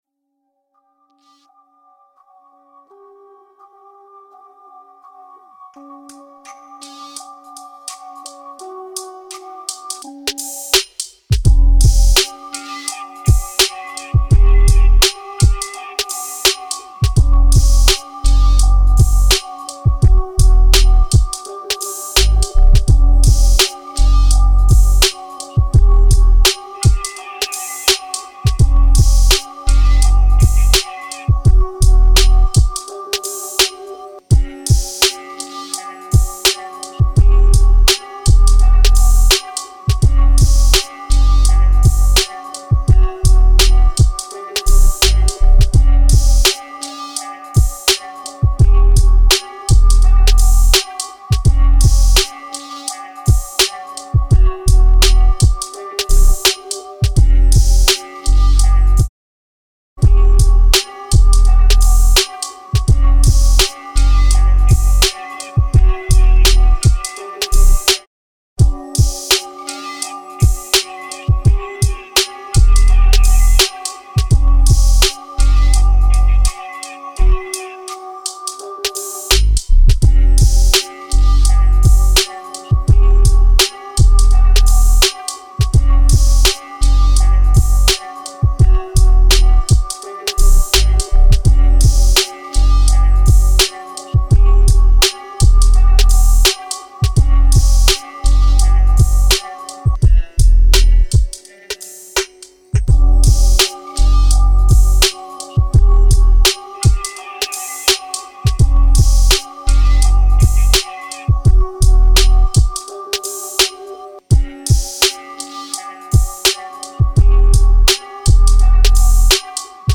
Инструментал и дребезжание
Суть вопроса такова, повторюсь, что воспроизводят колонки вроде бы также сносно все, что я делал и слушал до инцидента, слышу дребезжание (простите, "пердёж") вуфера в момент бас-линии, то в левой, то правой колонке на конкретной композиции, в данном случае данный инструментал.